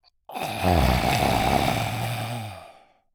Male_Low_Throat_01.wav